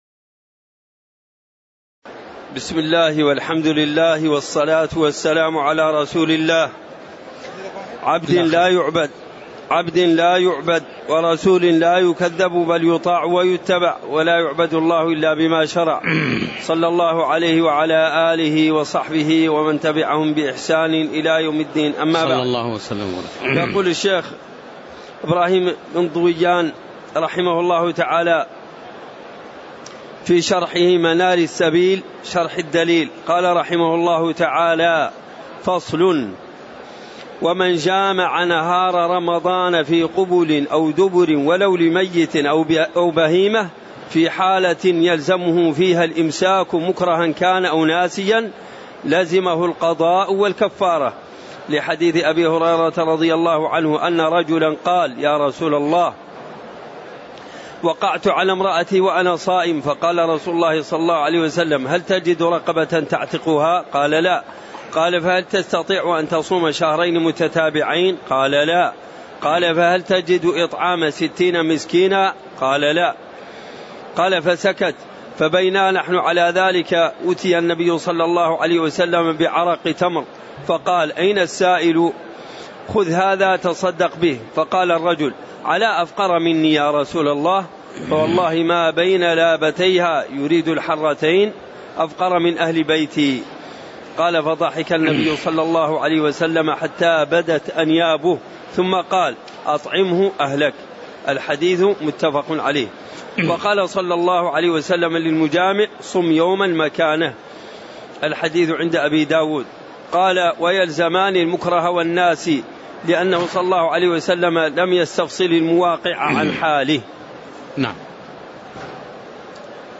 تاريخ النشر ١١ رمضان ١٤٣٨ هـ المكان: المسجد النبوي الشيخ